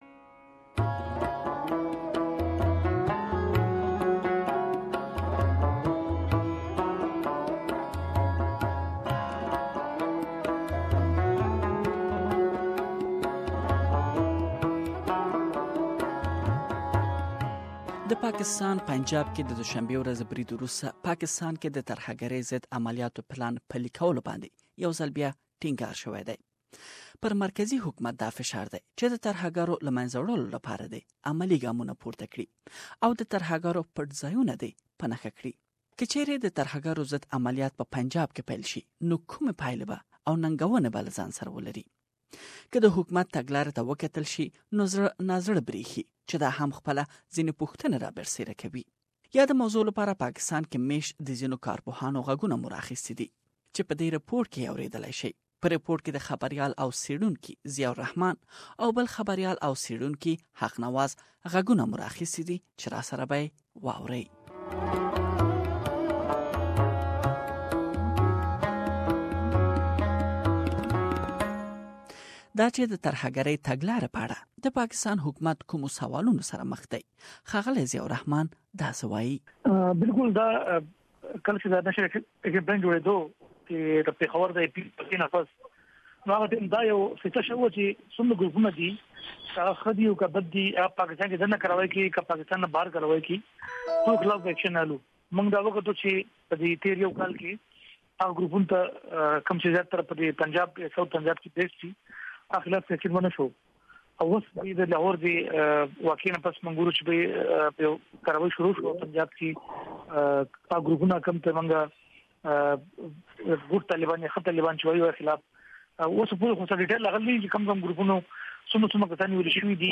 We have prepared a report using voices from Pakistan based journalist and analysts.